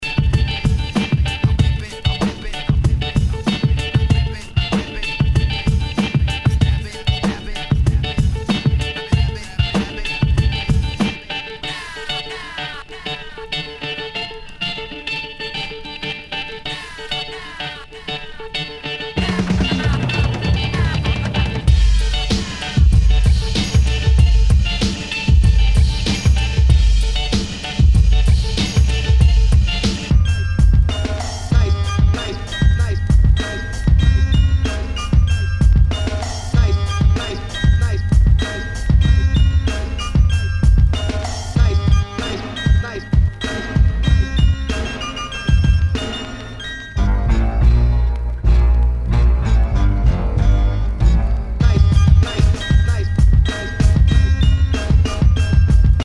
Nu- Jazz/BREAK BEATS
ナイス！ダウンテンポ / ブレイクビーツ！